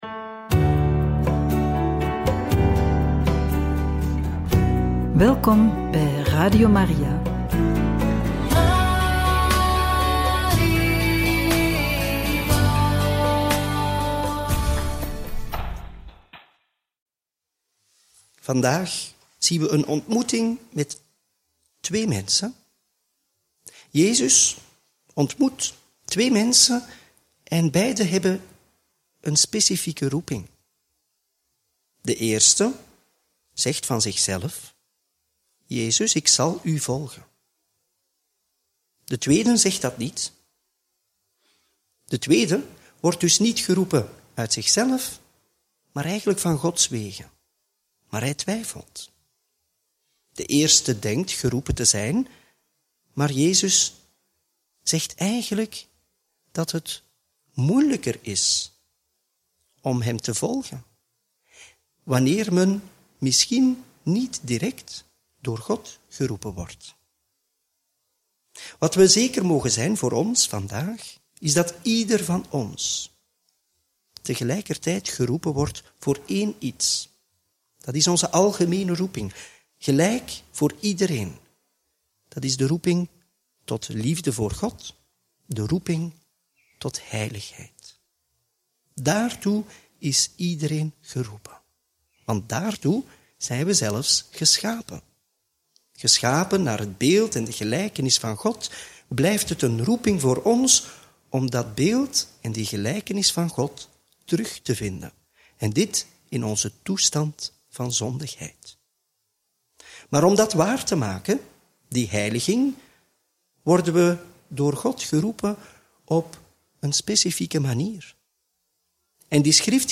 Homilie bij het Evangelie van maandag 30 juni 2025 – Mt 8, 18-22